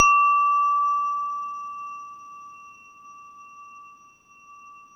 WHINE  D4 -R.wav